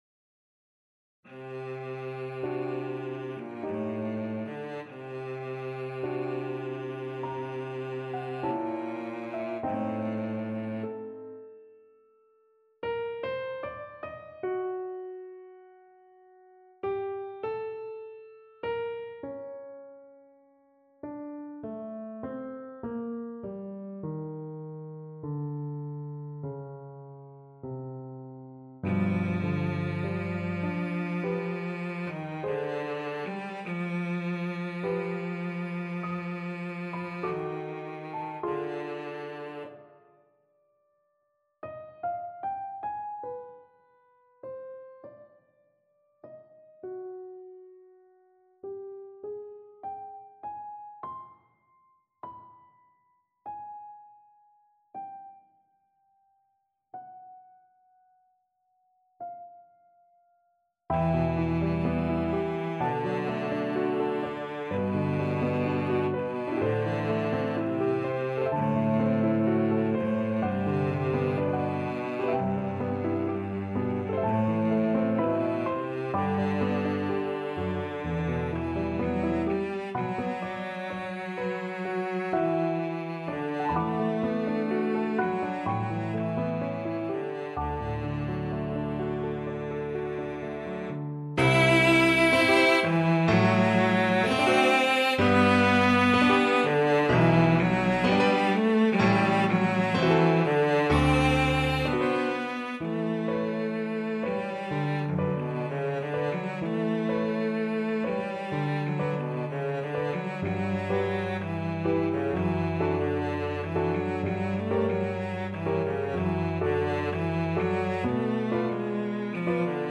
Lento espressivo =50
Eb3-E5
4/4 (View more 4/4 Music)
Classical (View more Classical Cello Music)